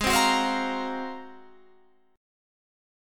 Ab13 chord diagram.
Listen to Ab13 strummed